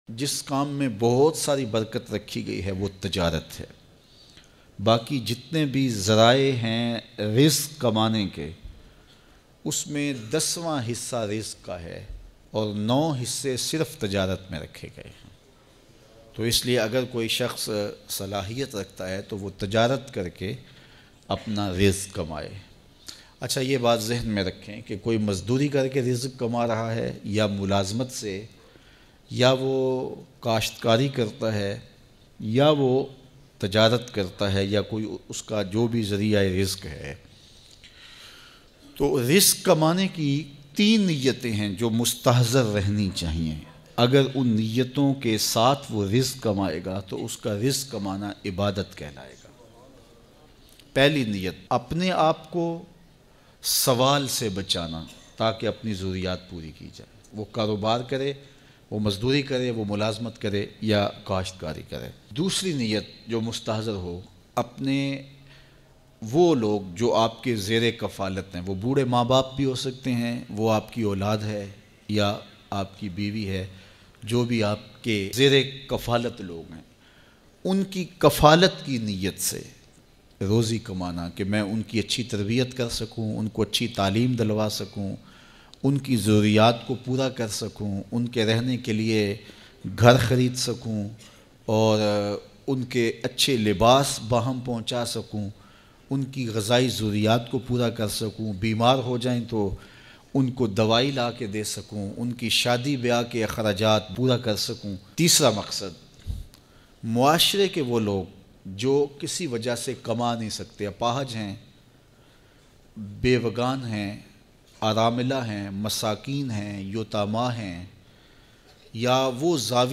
Bayan MP3